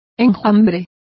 Complete with pronunciation of the translation of swarms.